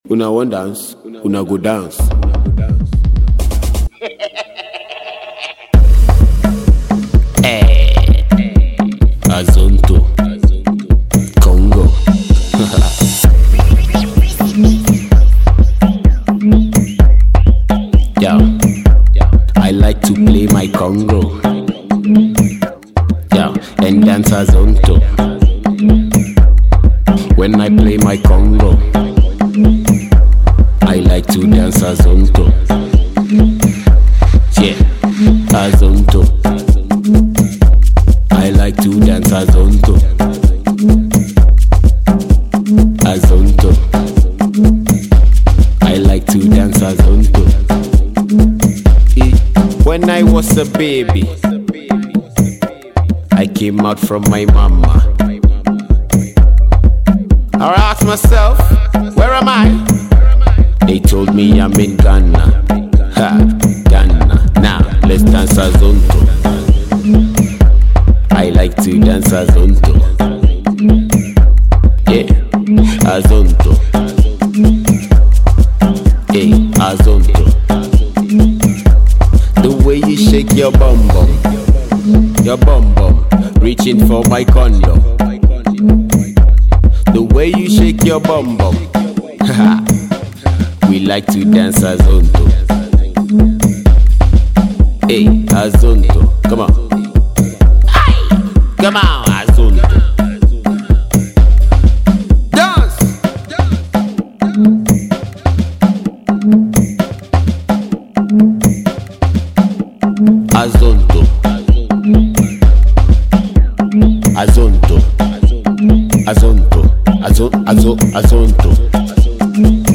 has all the elements of an African jam